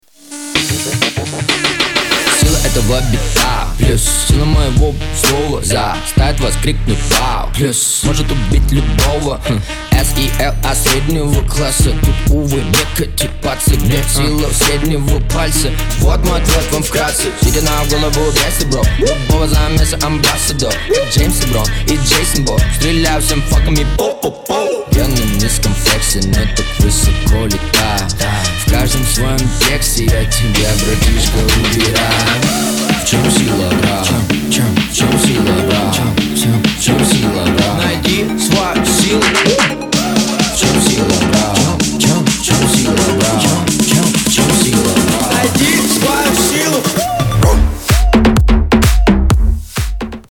Хип-хоп
Trap
Bass